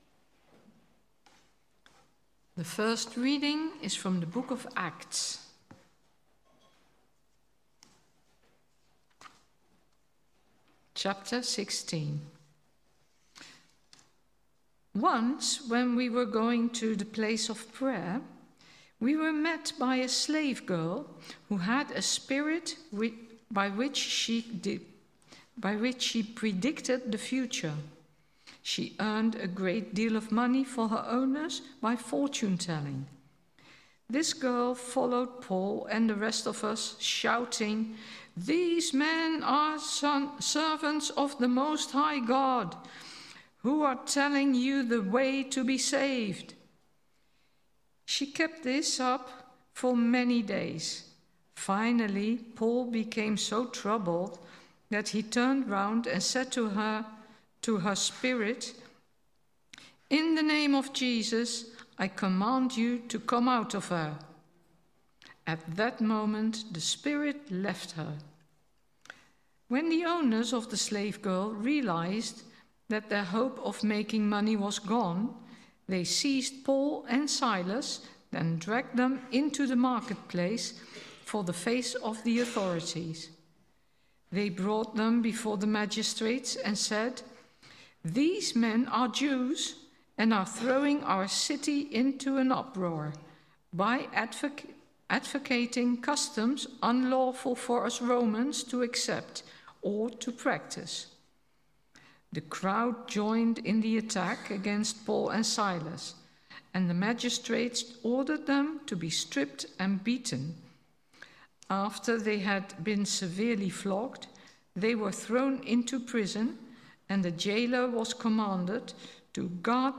Sermon – June 1st , 2025 This Sunday, we began a new series exploring the profound truths of the Nicene Creed —a declaration of faith shared across centuries and continents.